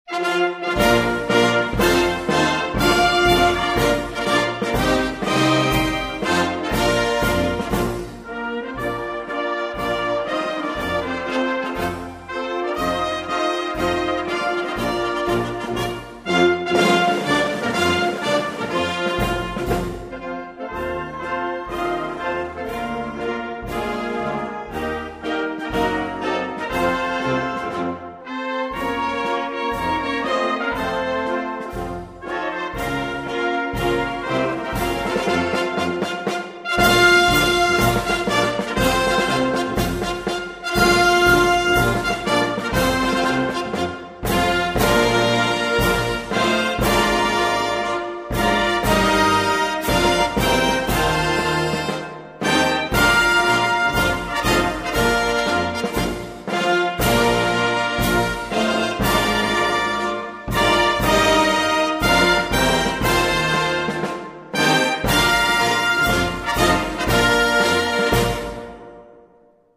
Другие рингтоны по запросу: | Теги: Гимн